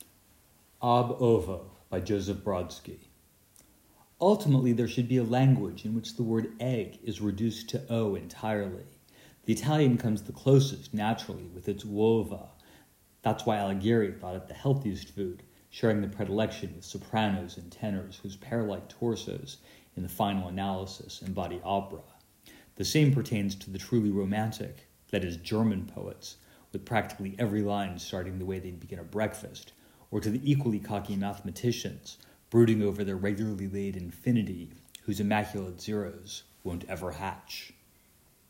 These poems reward reading aloud. Not to say I and my poor understanding of my iPad’s voice memo function do them justice, but here’s one called Ab Ovo which I quite like…